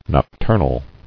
[noc·tur·nal]